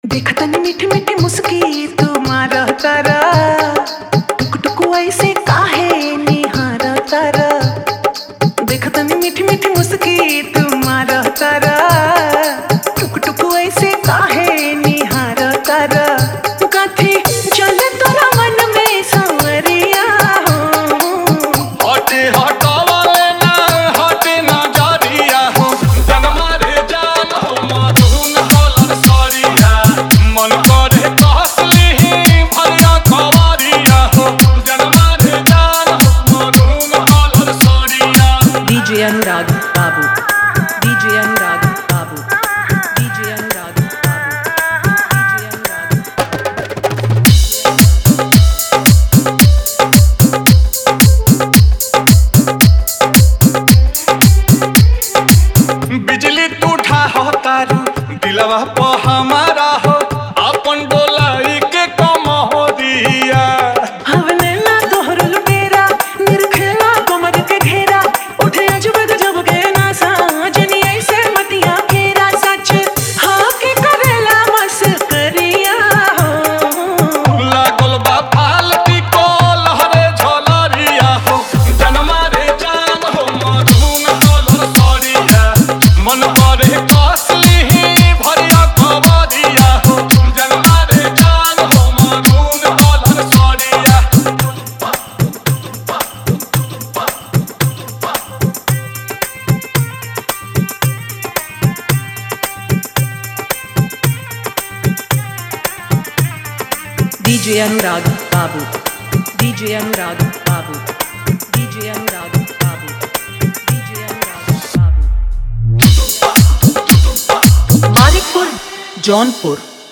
Category : dj remix songs bhojpuri 2025 new